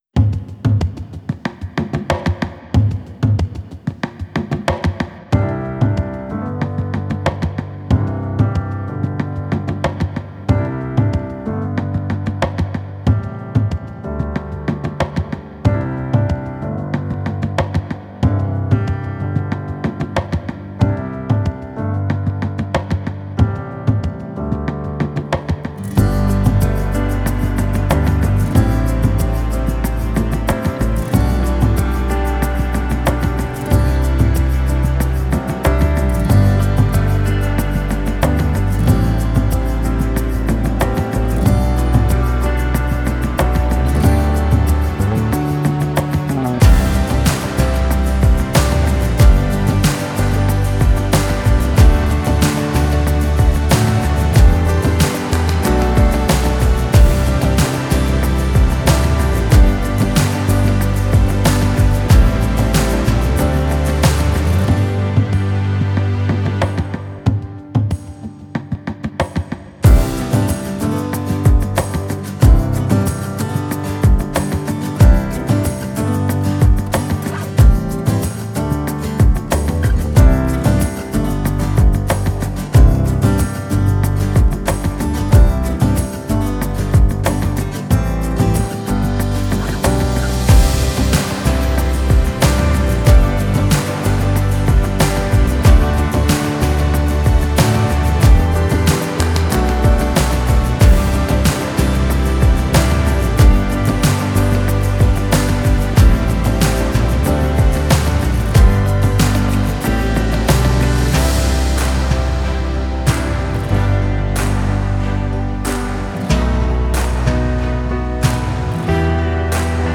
Gitara